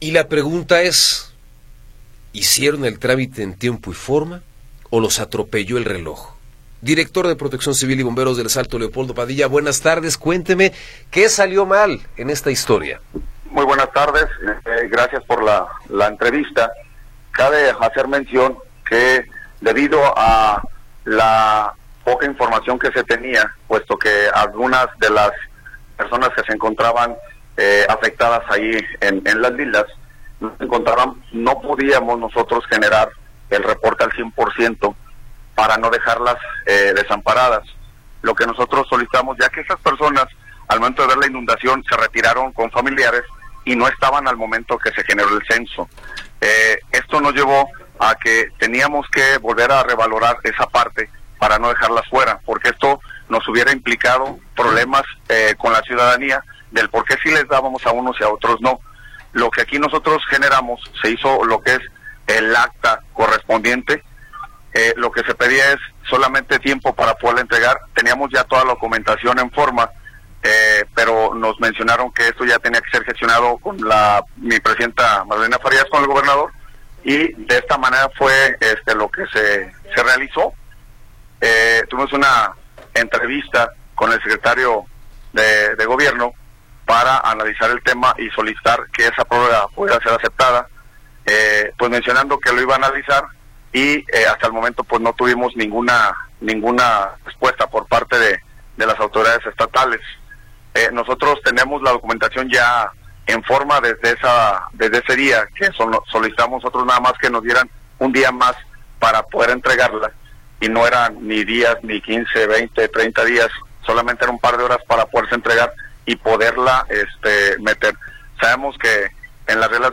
Entrevista con Leopoldo Padilla